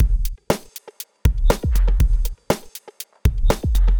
Lab Work (Beat) 120BPM.wav